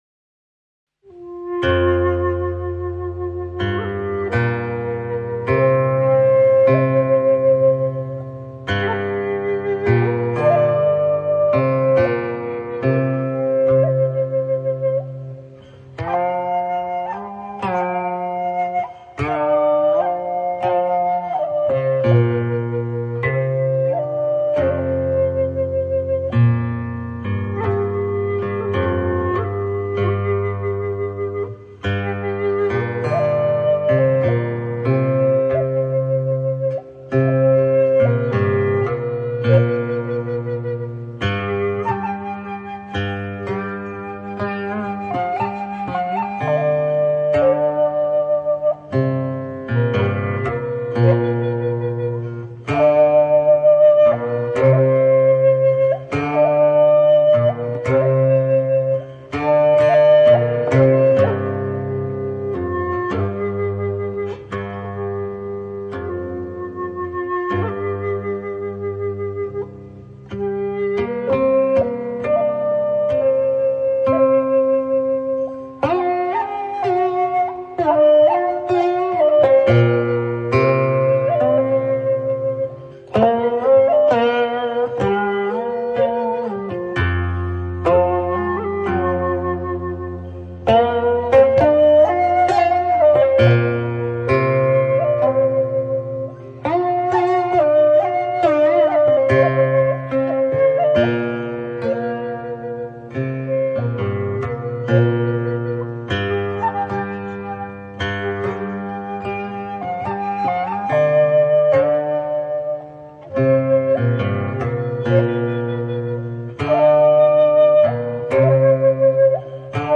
琴簫